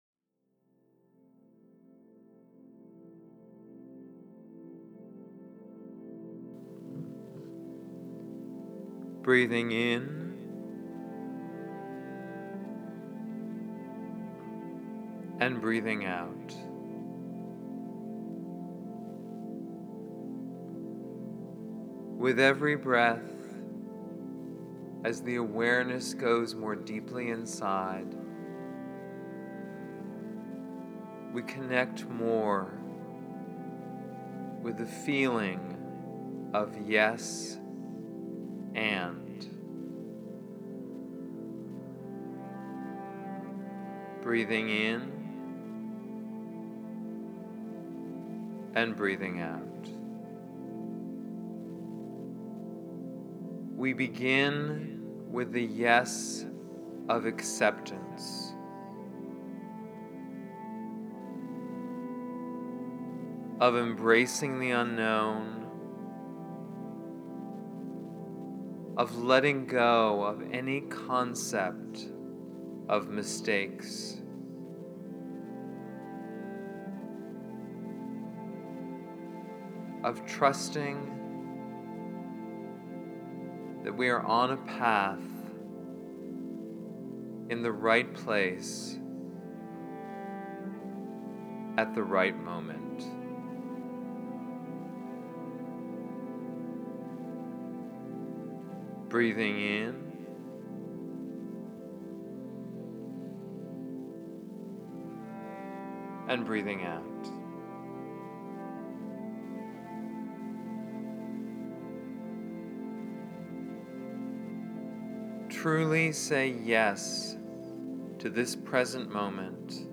Yes-And-Meditation.mp3